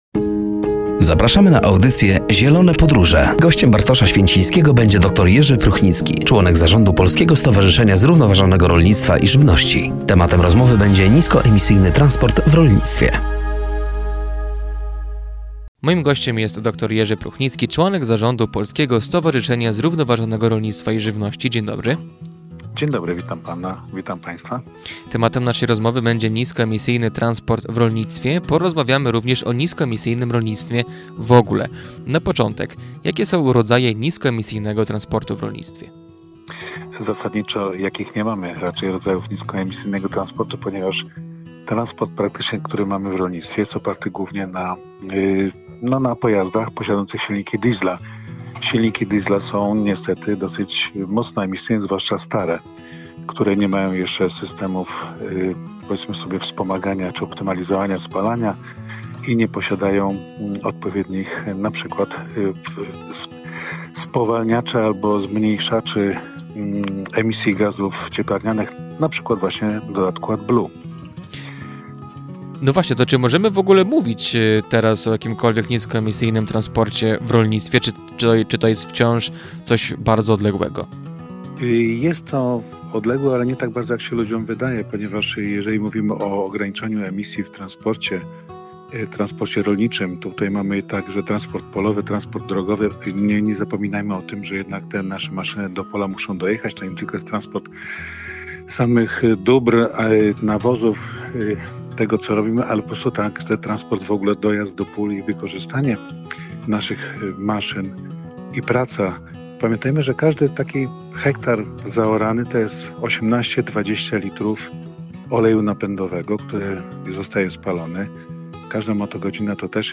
Rozmowa dotyczyła niskoemisyjnego transportu w rolnictwie.